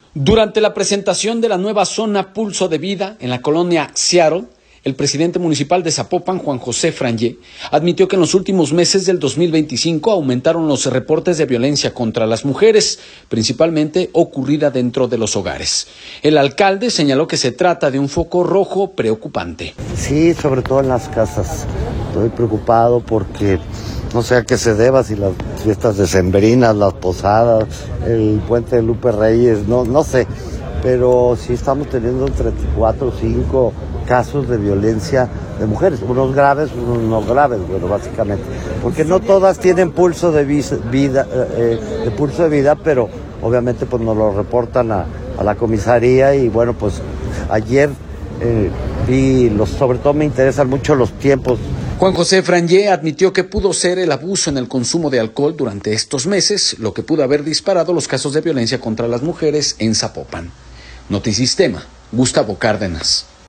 audio Durante la presentación de la nueva zona “Pulso de Vida” en la colonia Seattle, el presidente municipal de Zapopan, Juan José Frangie, admitió que en los últimos meses del 2025, aumentaron los reportes de violencia contra las mujeres, principalmente ocurrida dentro de los hogares. El alcalde señaló que se trata de un foco rojo preocupante.